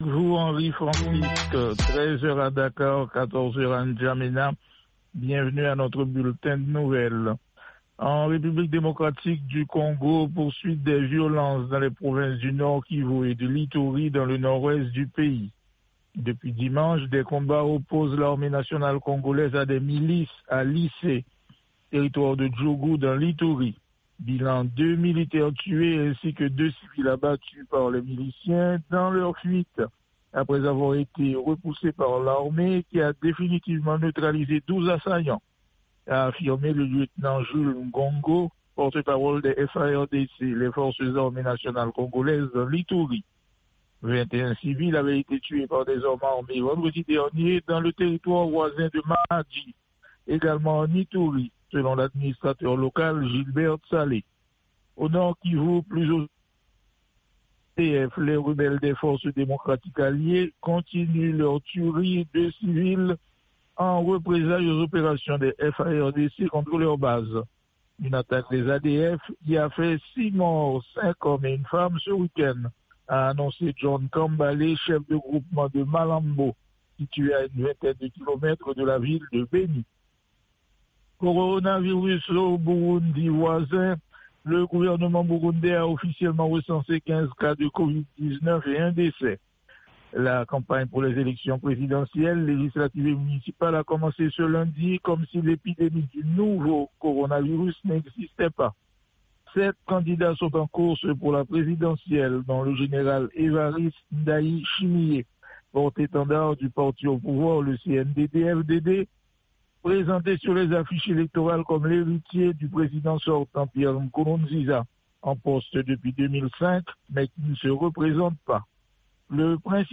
10 min Newscast